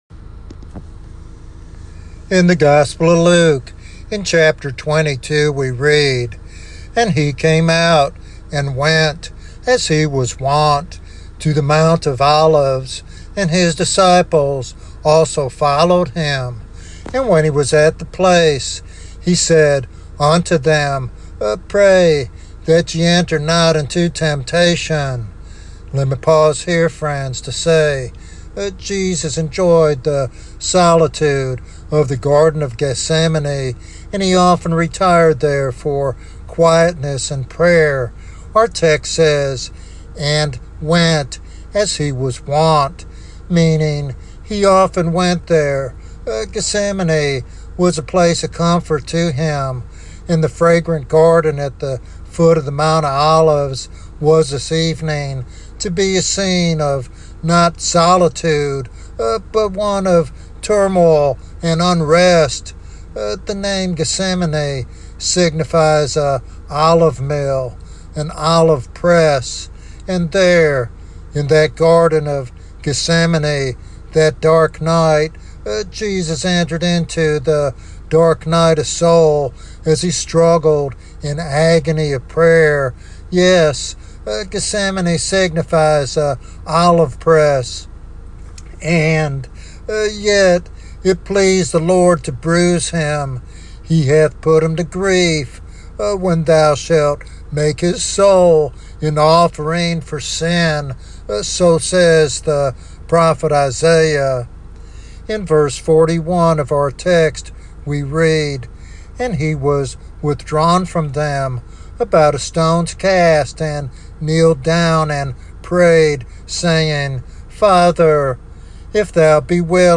In this moving sermon